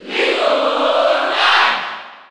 Category:Little Mac (SSB4) Category:Crowd cheers (SSB4) You cannot overwrite this file.
Little_Mac_Cheer_French_NTSC_SSB4.ogg